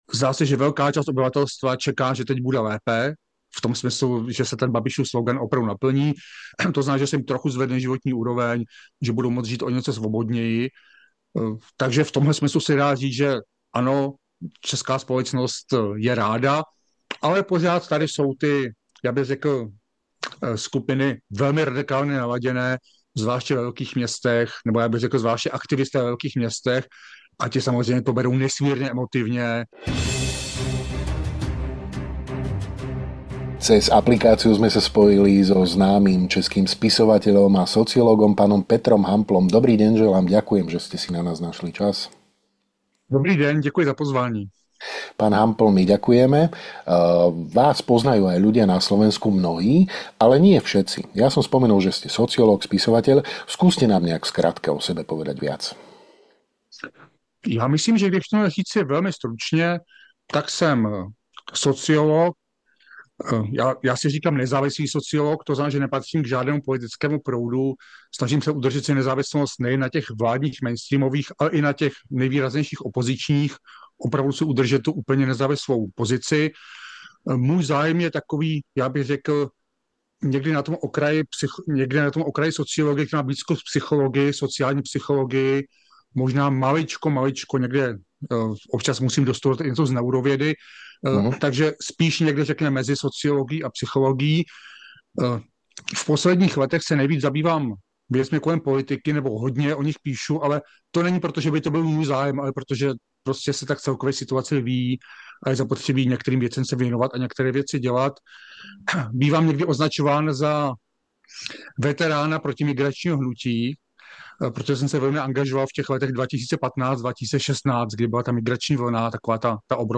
V rozhovore pre Hlavné správy nechýbajú ani témy napätia medzi Českom, Slovenskom a Maďarskom, možnosti obnovy spolupráce V4, ani vojnový konflikt na Ukrajine, ktorý sa pravdepodobne skončí až vyčerpaním jednej zo strán.